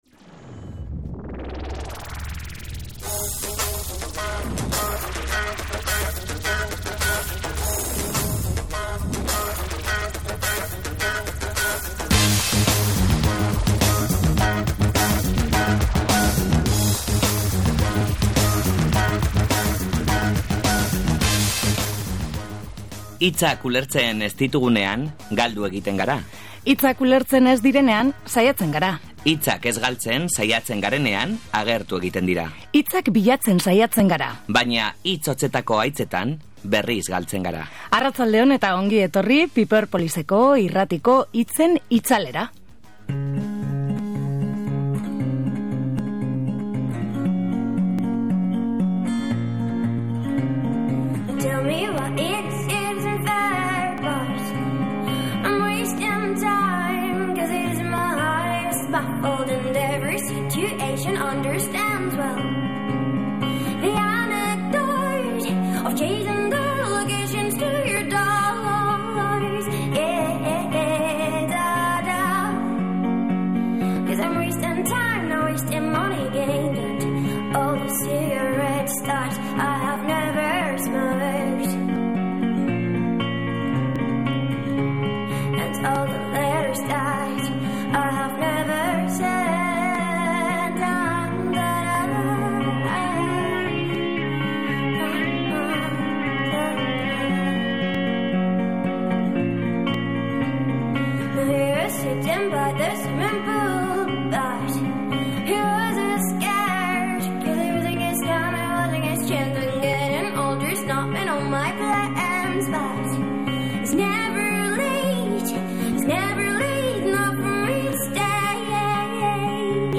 Disonancias proiektuaren inguruan solasaldia izan dugu. Disonancias ekimenak artistak eta Euskal Herriko enpresa berritzaileen artean kolaborazioak sortzen ditu.